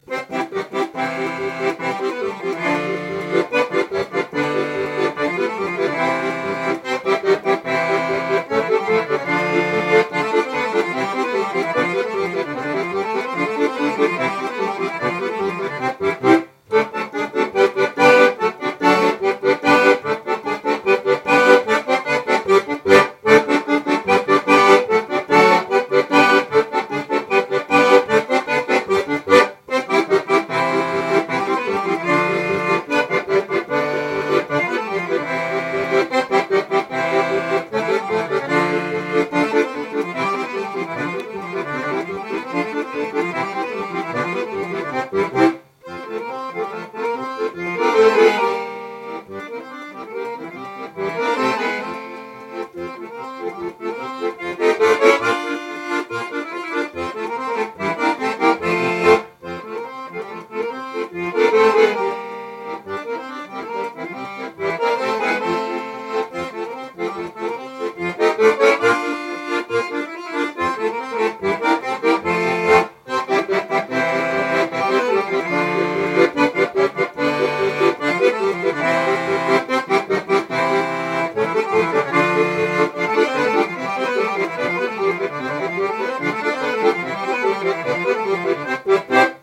My Awful Accordion Playing (You have been warned)